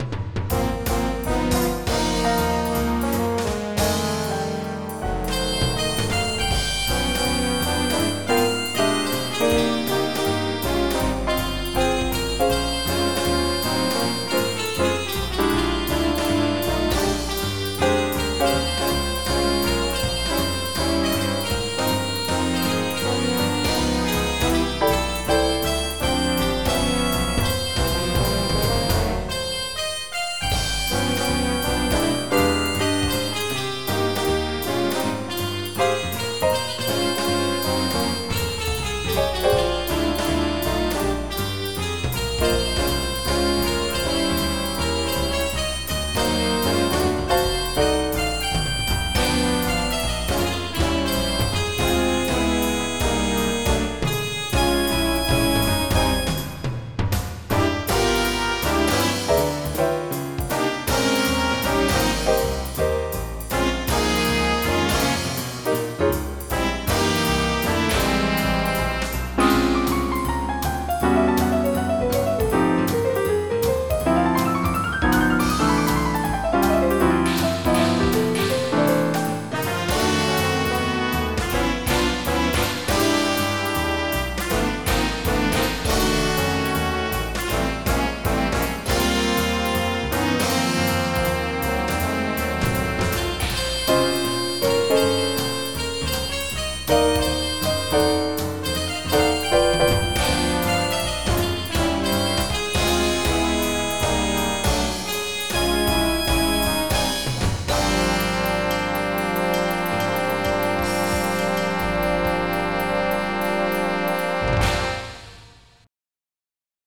Jazz
MIDI Music File
Someone's Band Type General MIDI